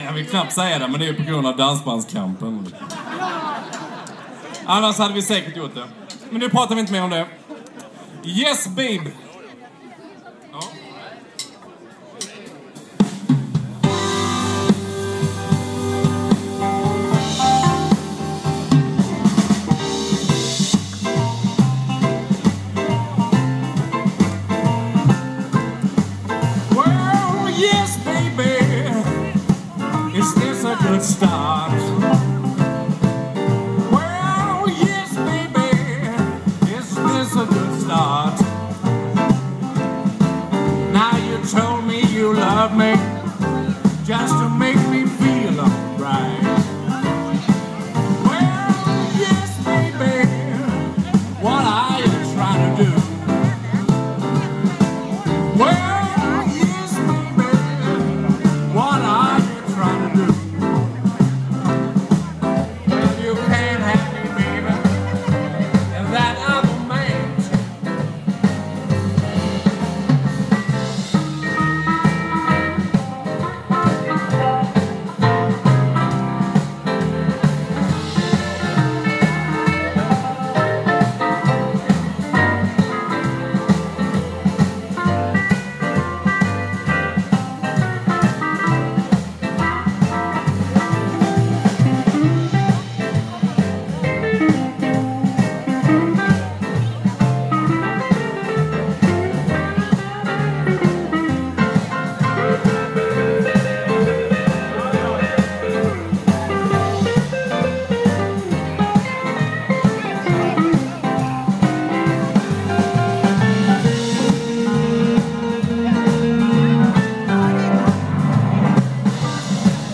blues quartet